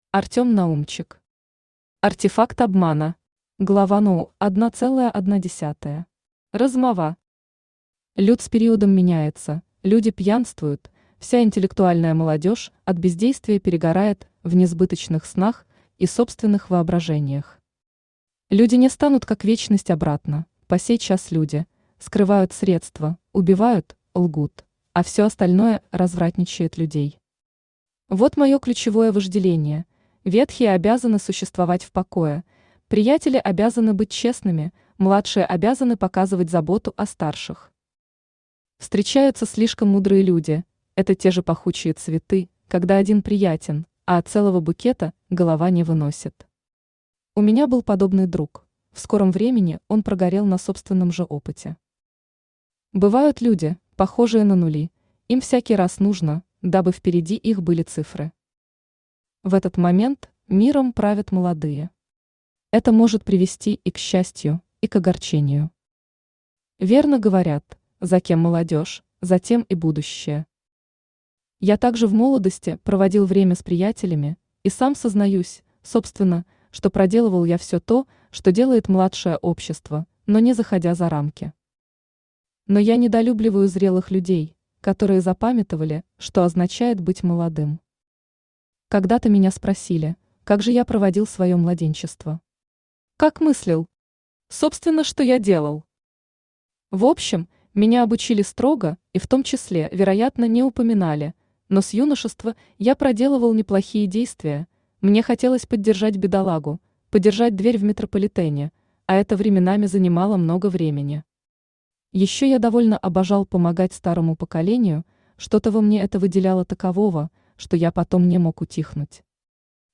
Аудиокнига Артефакт Обмана | Библиотека аудиокниг
Aудиокнига Артефакт Обмана Автор Артем Романович Наумчик Читает аудиокнигу Авточтец ЛитРес.